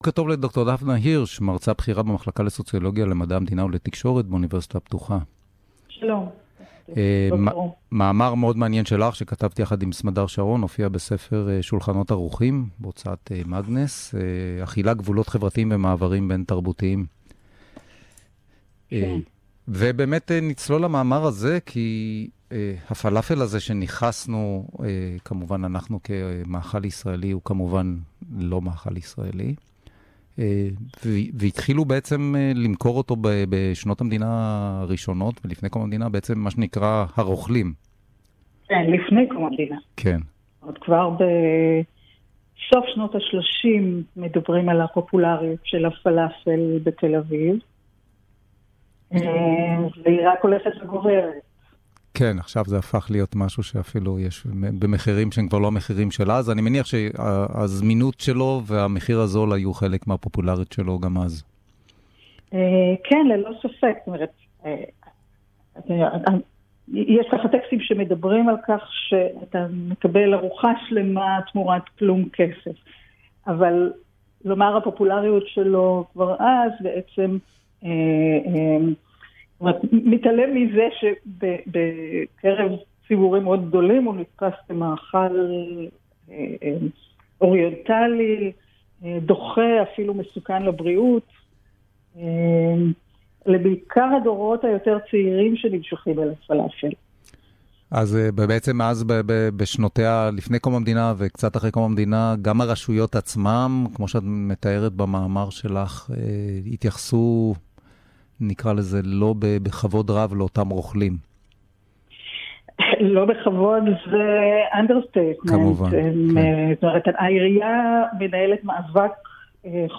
ריאיון